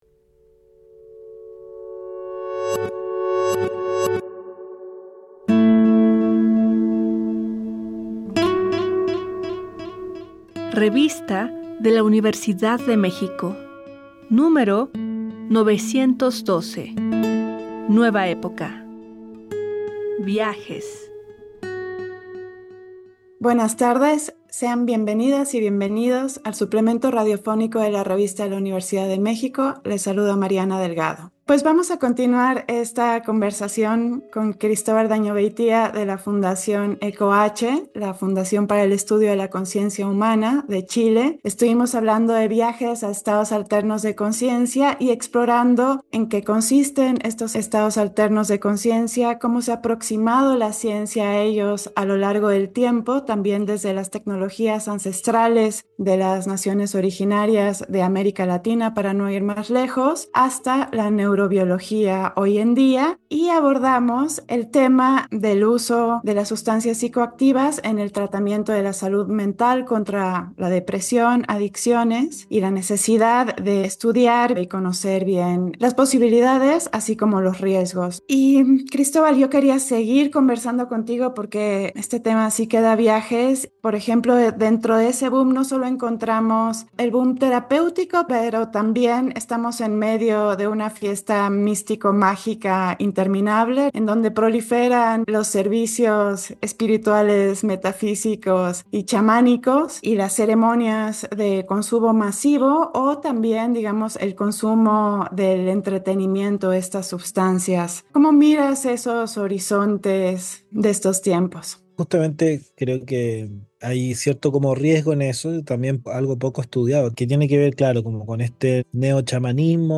Retomamos la conversación sobre los viajes a los estados no ordinarios de la consciencia y analizamos el fenómeno reciente del boom de químicos psicodélicos, utilizados como medio para la búsqueda de experiencias significativas o bien como parte de tratamientos alternativos a la farmacología psiquiátrica.
Fue transmitido el jueves 12 de septiembre de 2024 por el 96.1 FM.